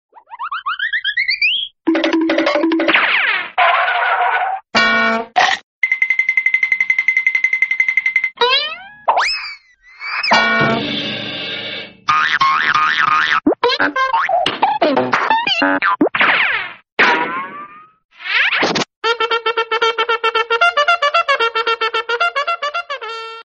نغمة كوميدية ومضحكة للمسجات – 01